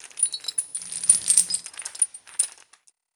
Chain Movement Intense.wav